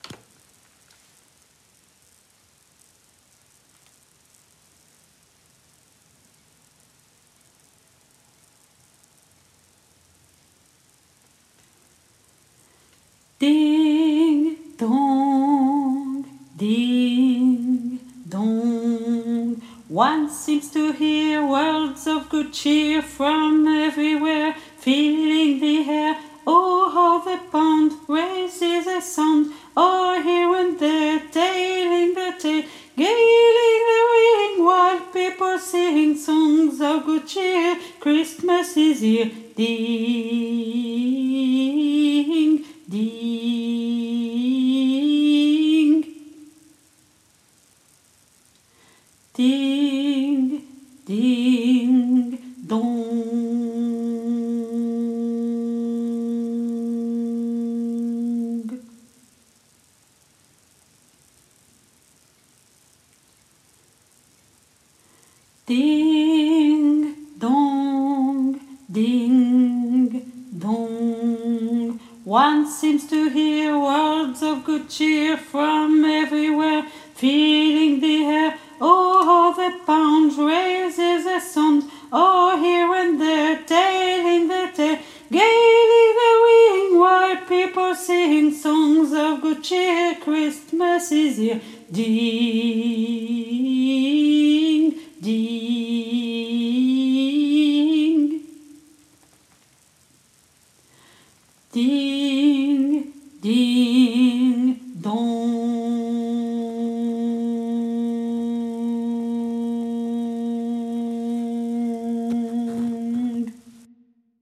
MP3 versions chantées
Alto